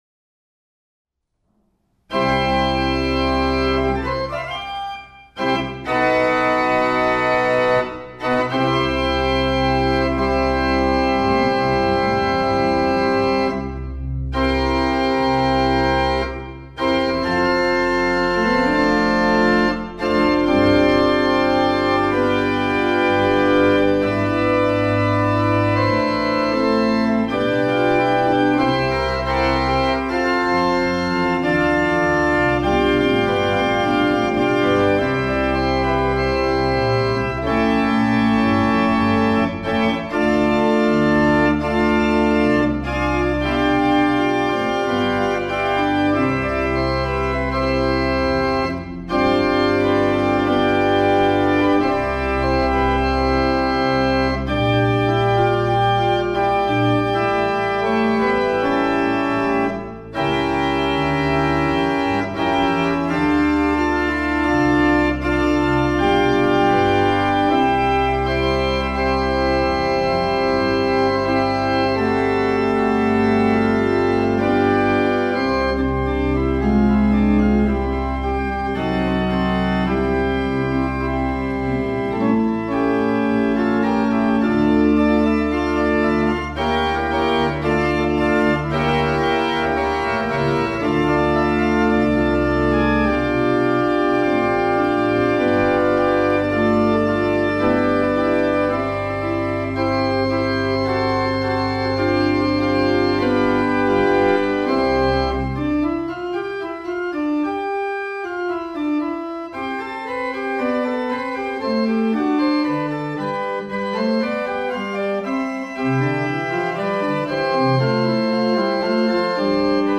Orgel Eitzendorf
Ausschnitte aus dem Konzert:
Improvisation "Backe, backe, Kuchen"